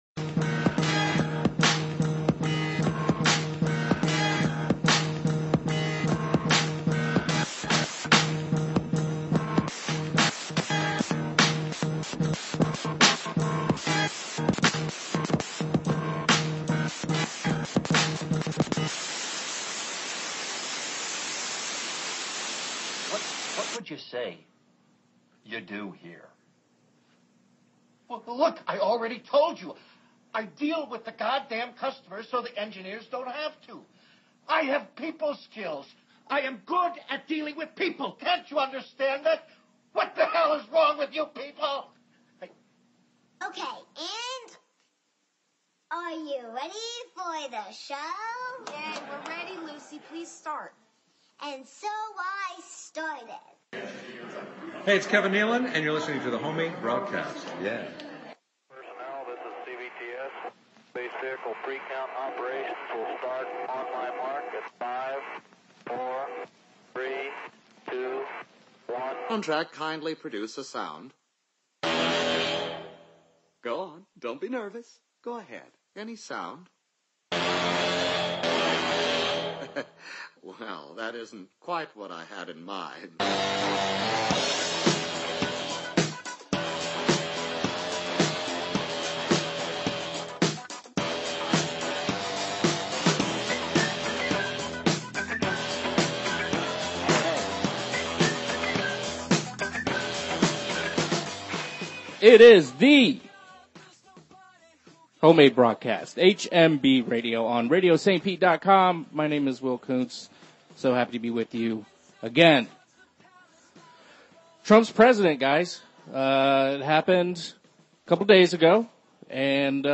1-22-17 Miami music trio Dénudés visit.